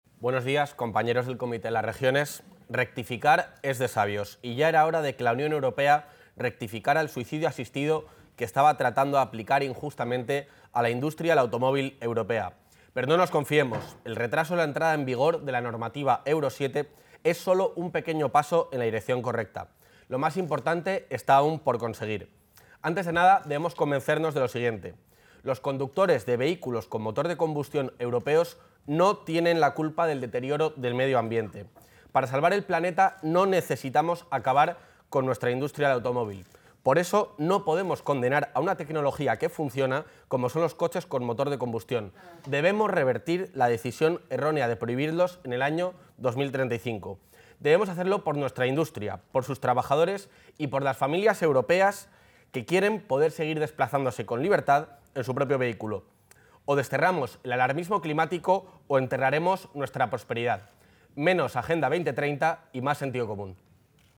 Intervención del vicepresidente.